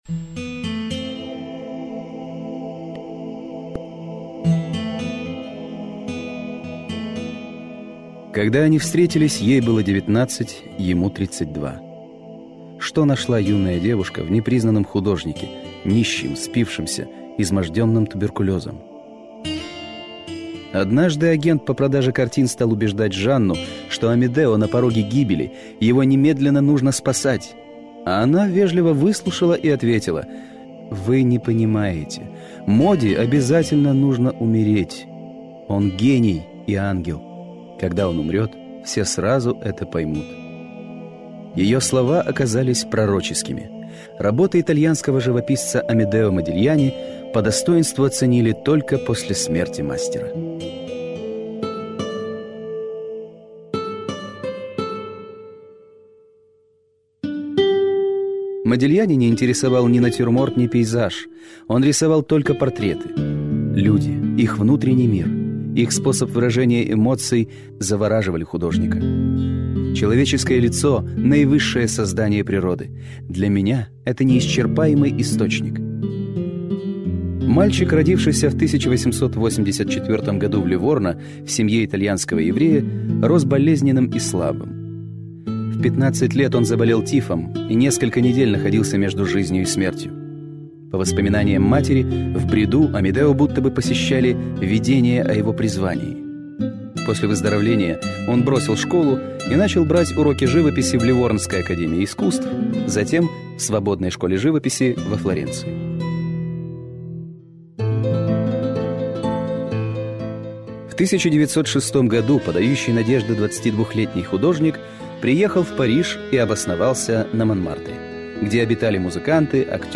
Начитка и музыкальная композиция к программе Amedeo Modigliani.